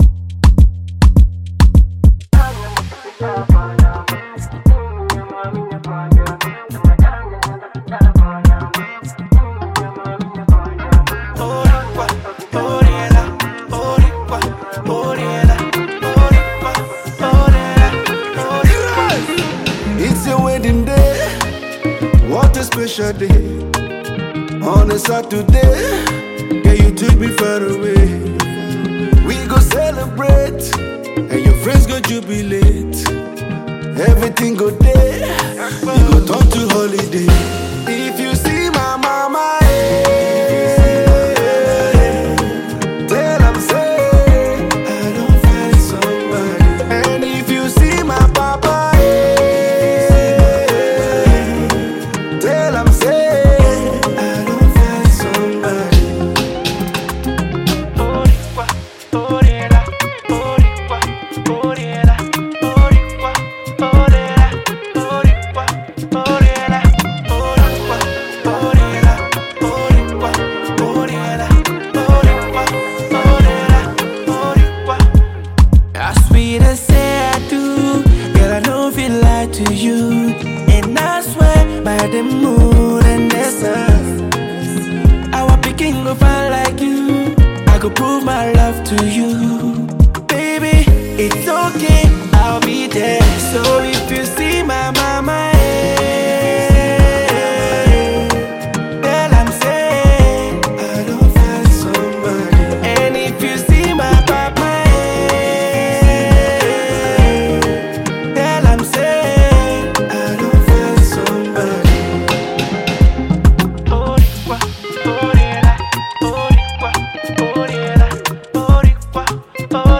Nigerian music duo